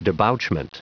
Prononciation du mot debouchment en anglais (fichier audio)
Prononciation du mot : debouchment